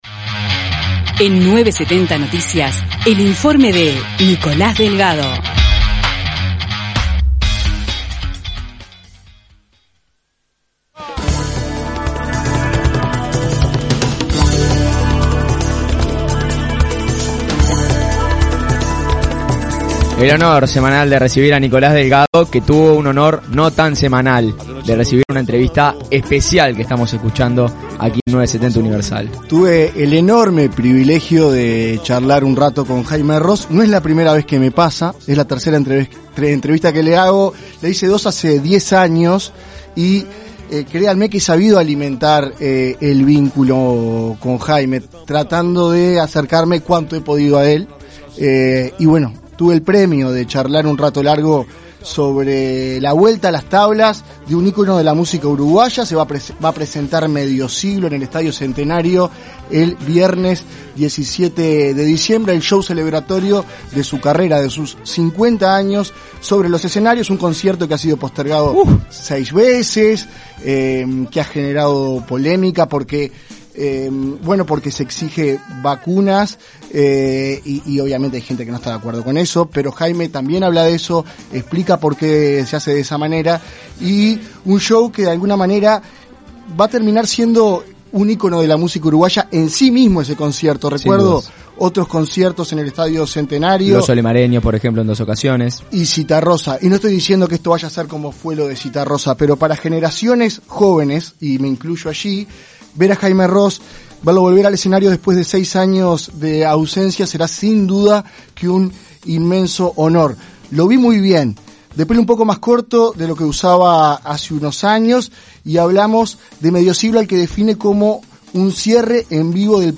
En 970 Noticias compartimos dos tramos de esa entrevista, uno sobre la elección de los músicos y otro sobre los arreglos a las canciones que interpretarán.